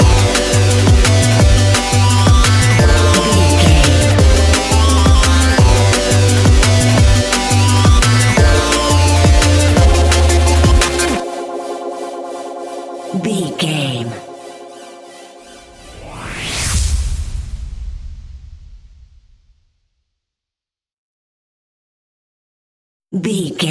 Ionian/Major
F♯
electronic
techno
trance
synths
synthwave
instrumentals